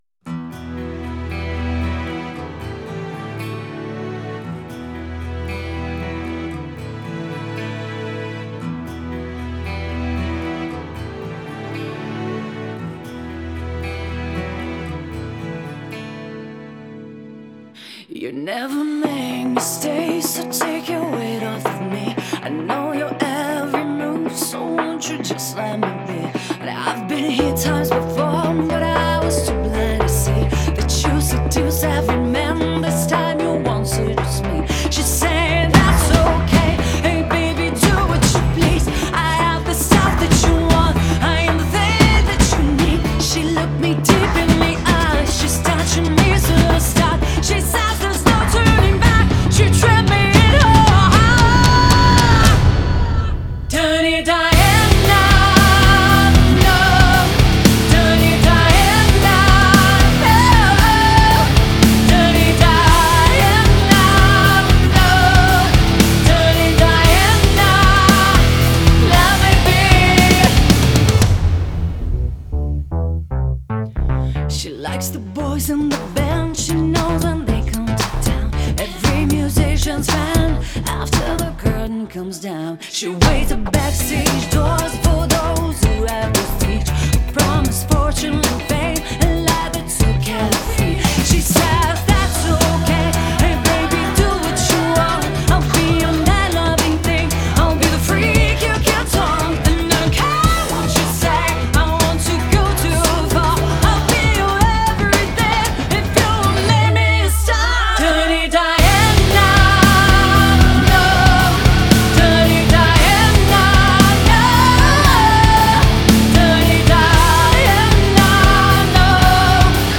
.. аранж , сведение , гитары мое )